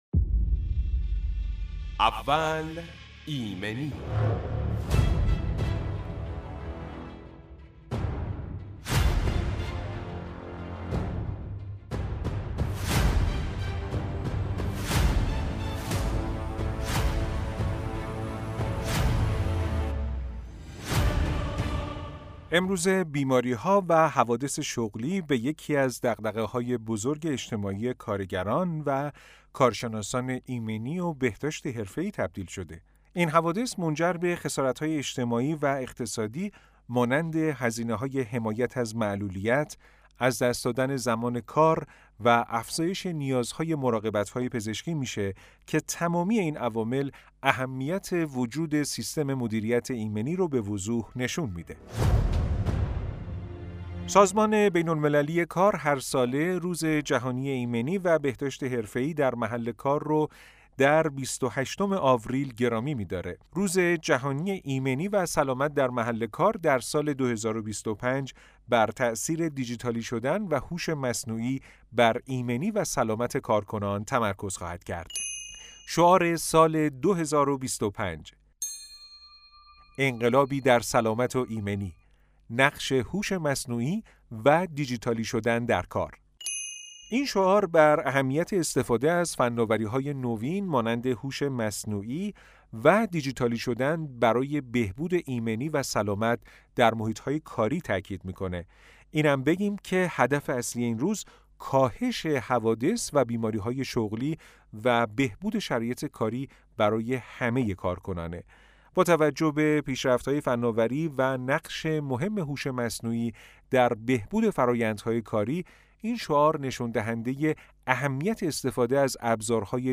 به مناسب همین روز، در خدمت دو کارشناس محترم هستیم تا در مورد شعار جهانی و نقش ایمنی و سلامت در محیط کار و جامعه بیشتر بدونیم.